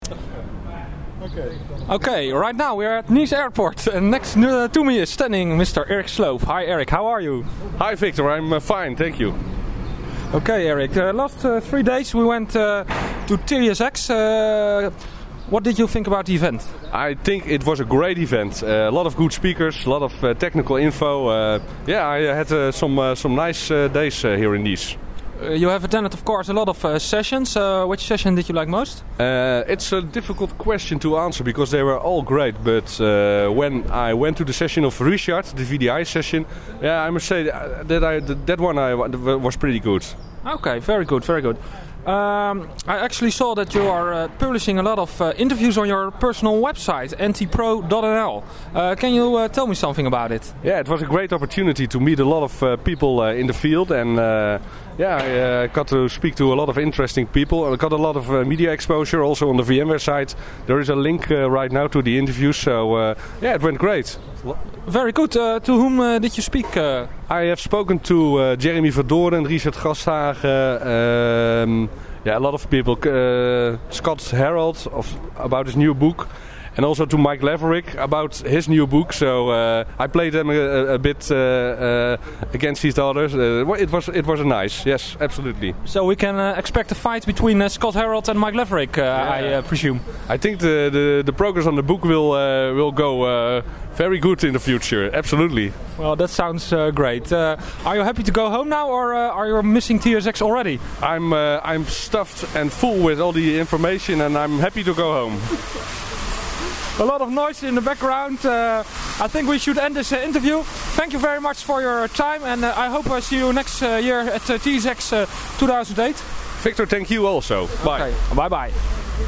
At the airport